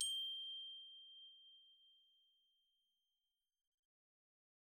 glock_medium_G6.wav